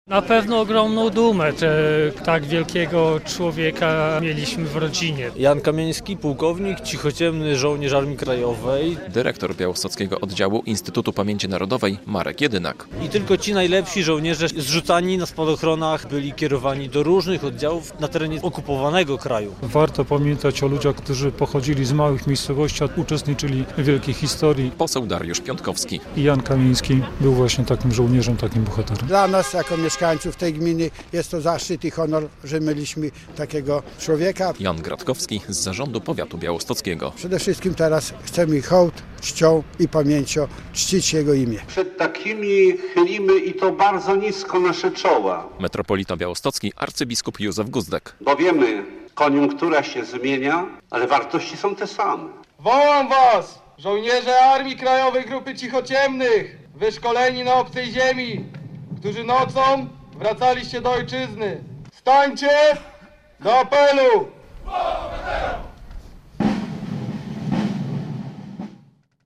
W Tryczówce w gminie Juchnowiec Kościelny w niedzielę (10.03) została odsłonięta tablica poświęcona płk. Janowi Kamieńskiemu. To jeden z 316 Cichociemnych Spadochroniarzy Armii Krajowej, którzy byli prekursorami polskich sił specjalnych.